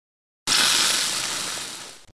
Звуки чужого
Шипит